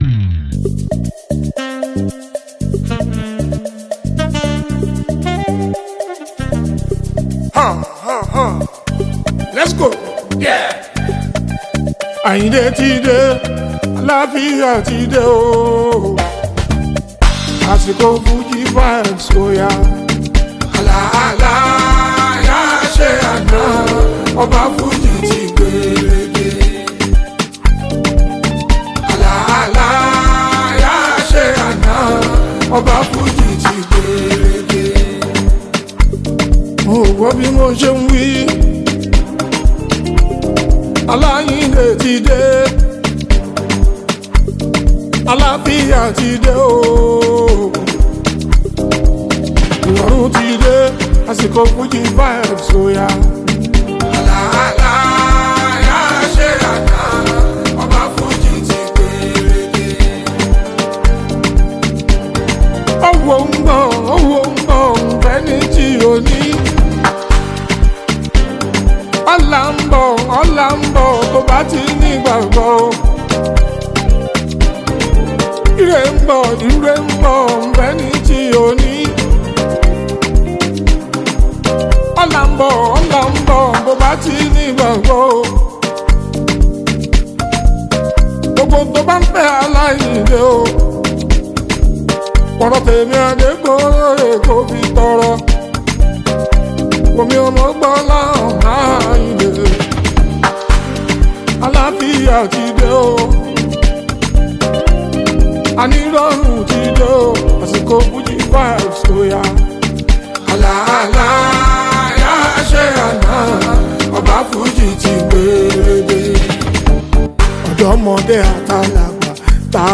Veteran Fuji icon
Fuji music
well-arranged instrumental